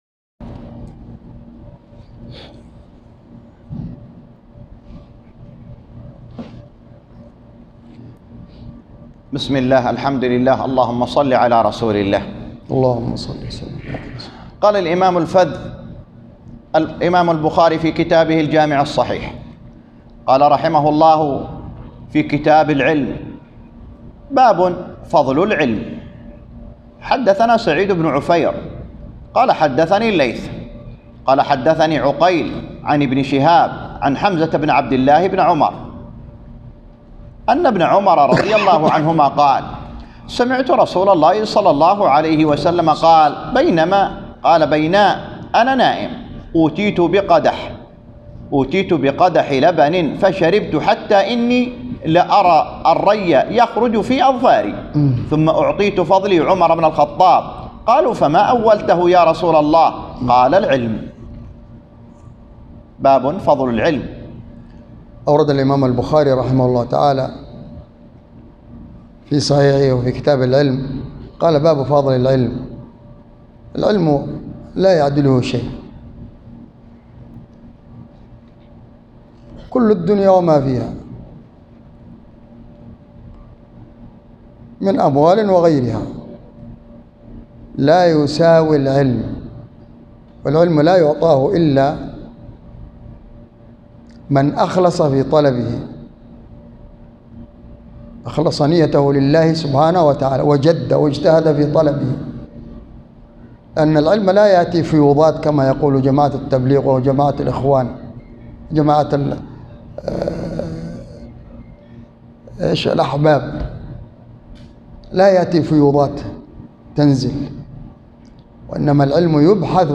شرح صحيح البخاري كتاب العلم - بجامع الخير بأبوعريش